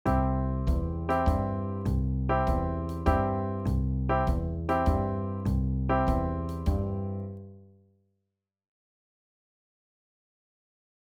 ジャズワルツは、3拍子のゆったりした揺れのあるグルーヴが特徴。
ここではジャズセッションでも演奏しやすいバラードよりのゆったりジャズワルツを紹介します
• 1拍目にコードを強く弾いて円をかくようなイメージで演奏するのがおすすめです
ワルツ.wav